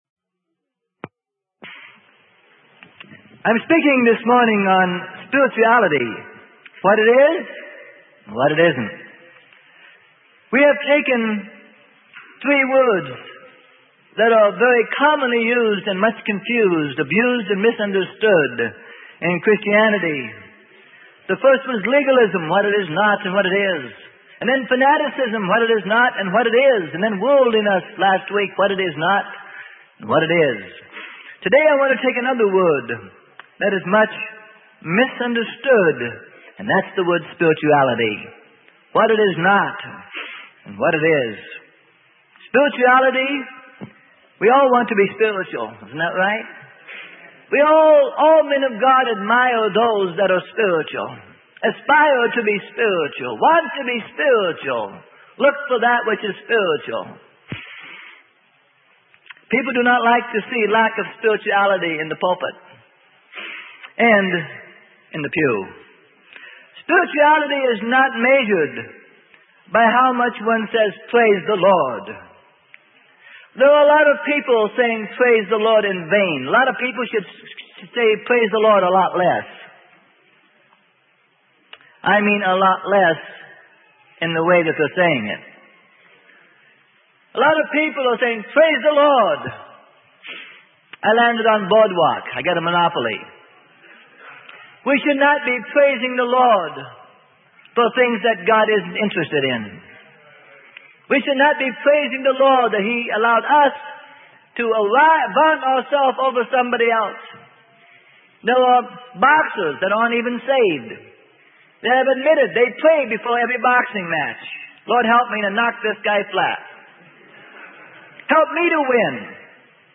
Sermon: Spirituality - What It Is and Is Not - Freely Given Online Library